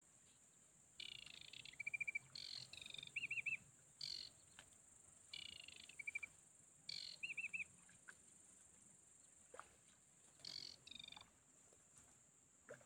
Putni -> Ķauķi -> 6
Grīšļu ķauķis, Acrocephalus paludicola
StatussDzied ligzdošanai piemērotā biotopā (D)